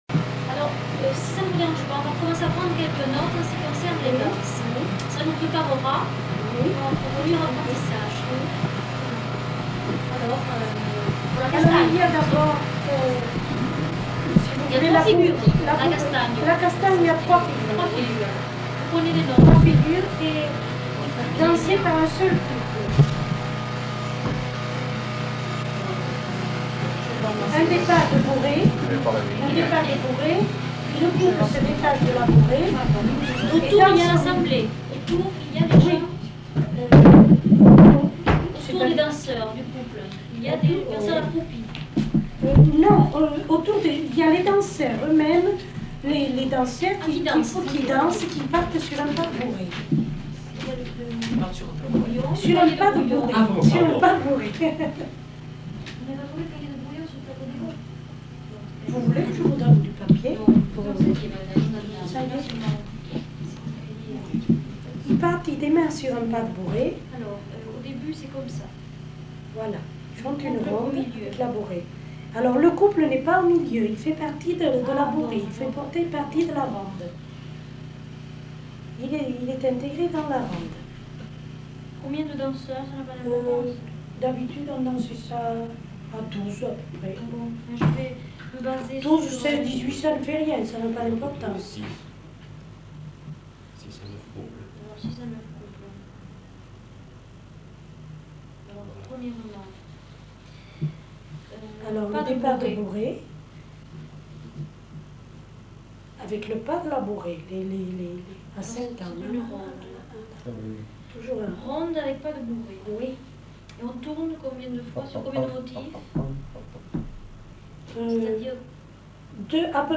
Description de la castanha (avec air fredonné)